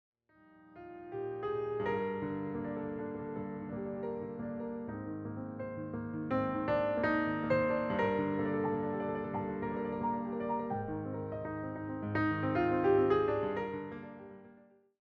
all reimagined through solo piano.